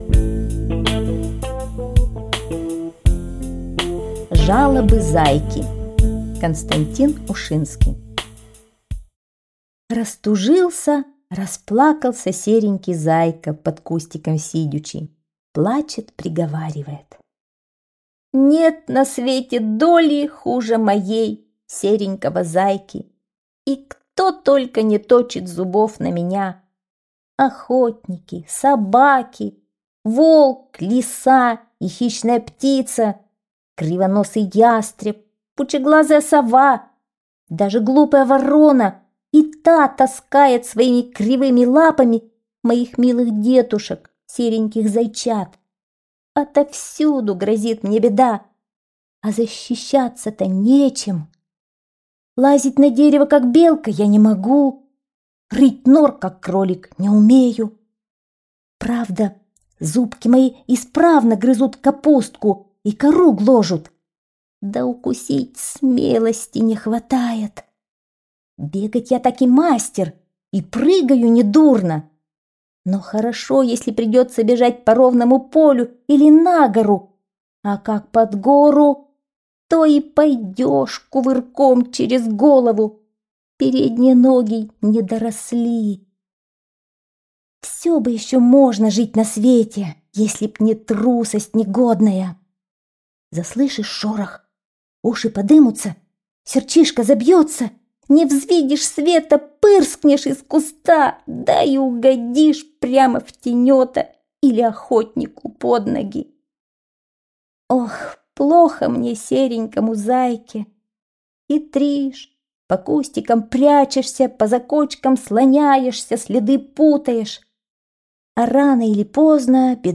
Жалобы зайки - аудио рассказ Ушинского К.Д. История о том, как серенький зайка плакал под кустом о том, что отовсюду ему грозит беда...